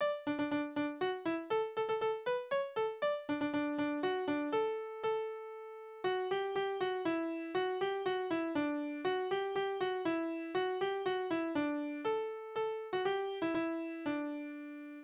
Rückelreih Tanzverse: Tonart: D-Dur Taktart: 3/4 Tonumfang: Oktave Besetzung: instrumental
Vortragsbezeichnung: Polonäsezeitmaß